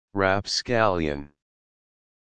Audio Pronunciation of rapscallion
rapscallion.mp3